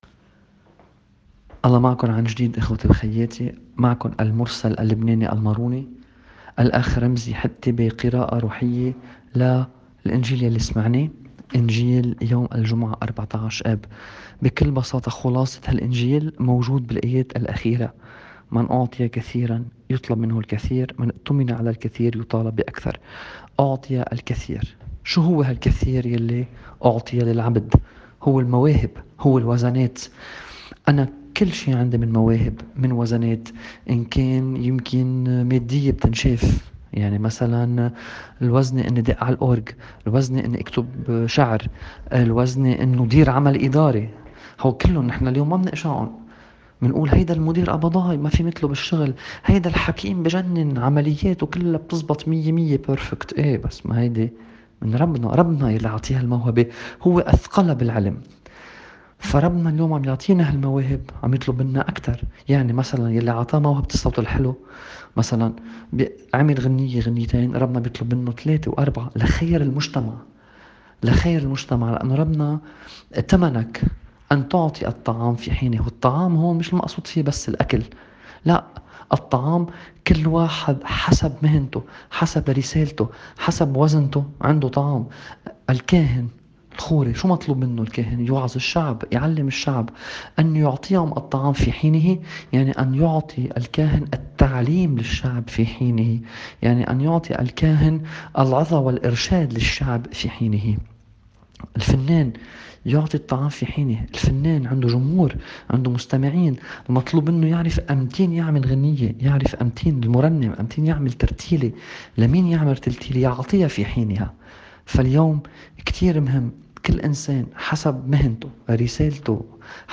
تأمل في إنجبل يوم ١٤ آب ٢٠٢٠
تأمّل بإنجيل ١٤ آب ٢٠٢٠.mp3